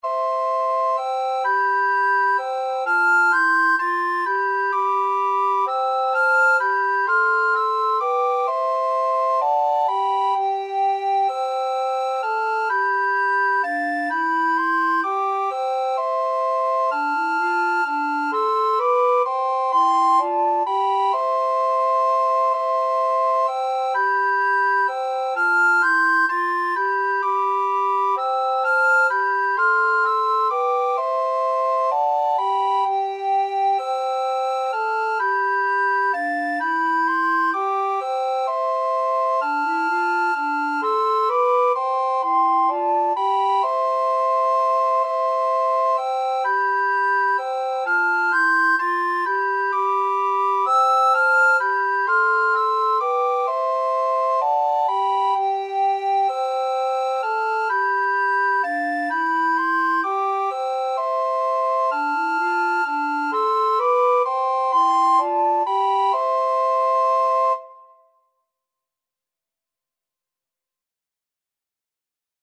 Free Sheet music for Recorder Trio
Soprano RecorderAlto RecorderTenor Recorder
C major (Sounding Pitch) (View more C major Music for Recorder Trio )
3/4 (View more 3/4 Music)
Traditional (View more Traditional Recorder Trio Music)